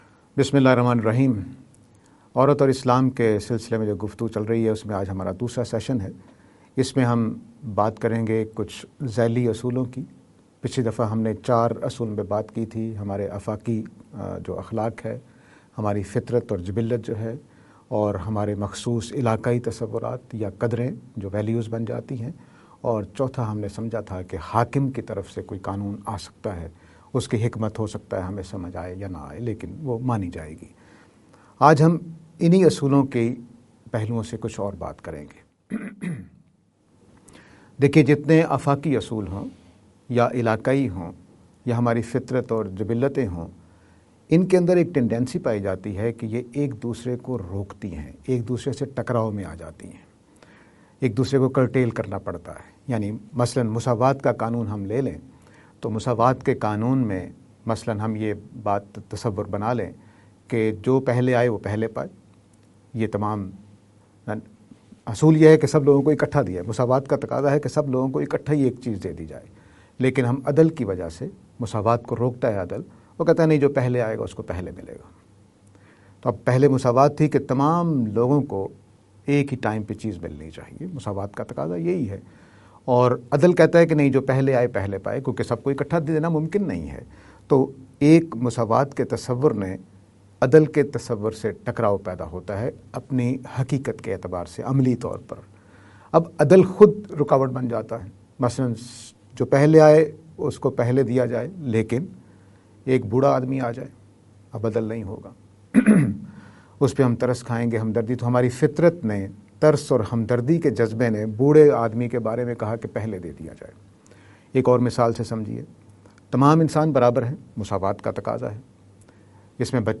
lecture series on "Women and Islam".